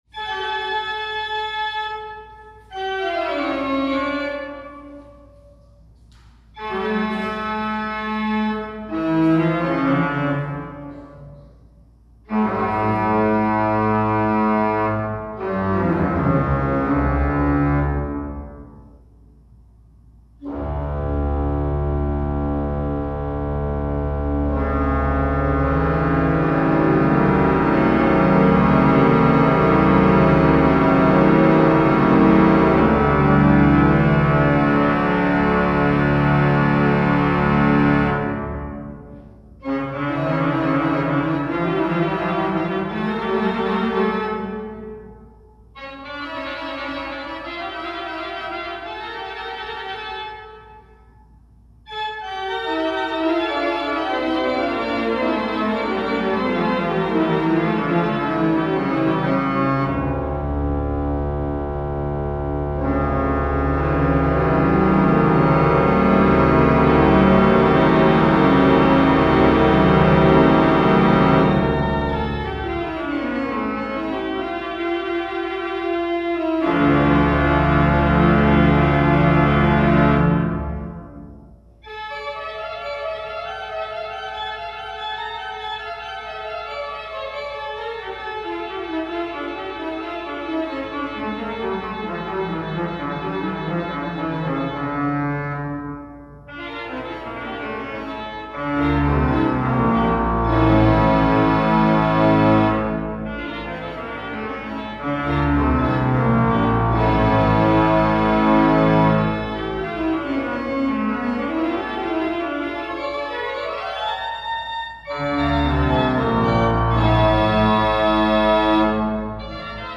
Бинауральная музыка
Toccata e fuga in re minore (орган разумеется) -